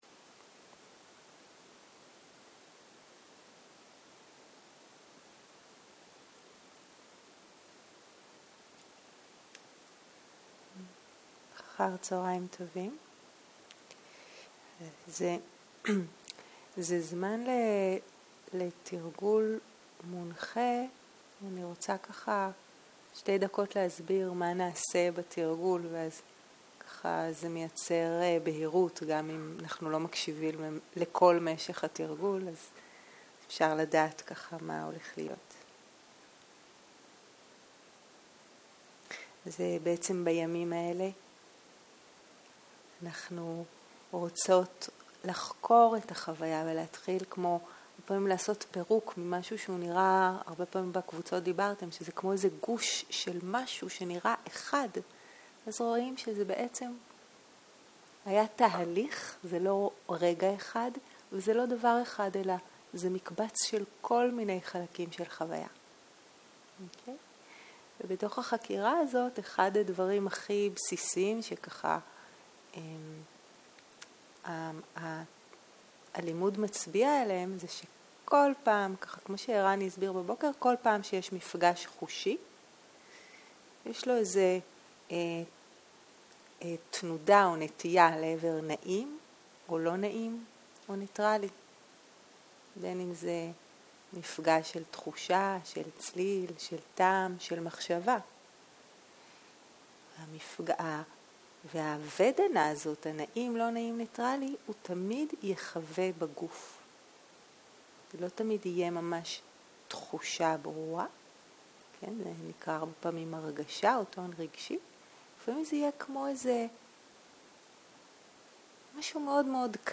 צהריים - מדיטציה מונחית - ודאנה, התבוננות בטון התגובה
סוג ההקלטה: מדיטציה מונחית